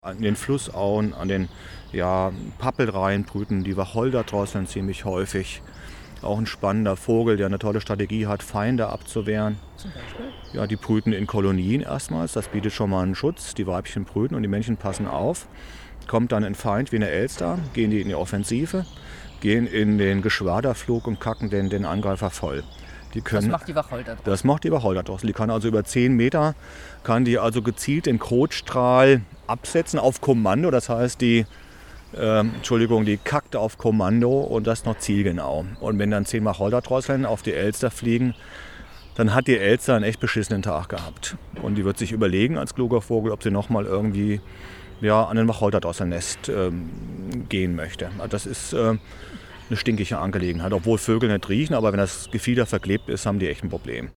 wacholderdrossel_cut2.mp3